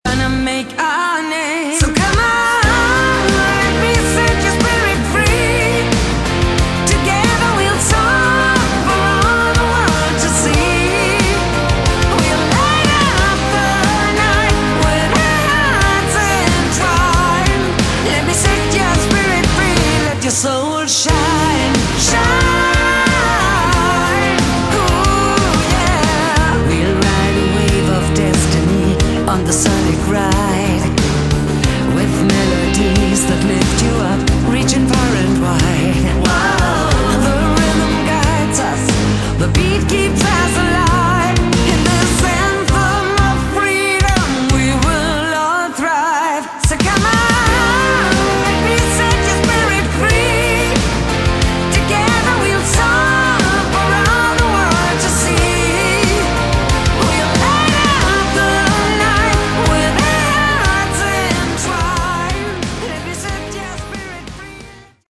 Category: Hard Rock
Vocals, Backing Vocals
Guitar, Bass, Keyboards
Drums, Percussion